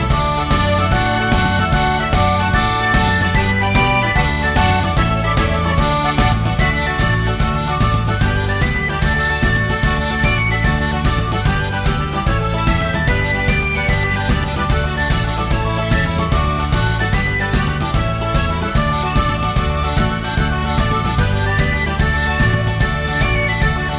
no Backing Vocals Irish 2:40 Buy £1.50